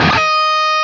LEAD D#4 LP.wav